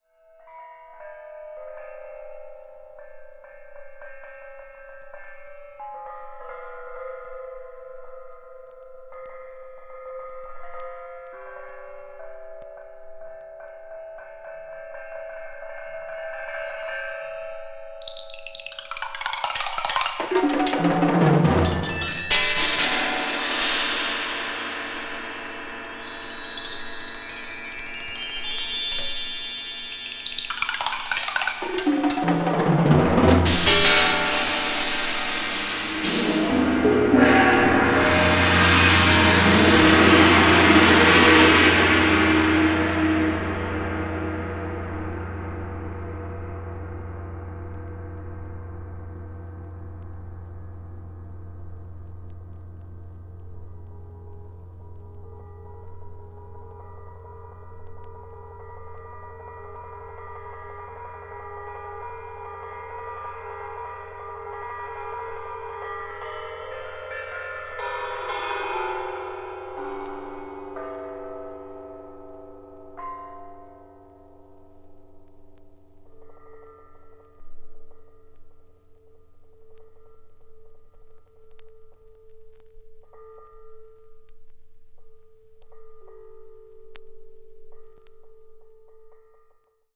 media : EX/EX(わずかにチリノイズが入る箇所あり)
20th century   contemporary   percussion solo   post modern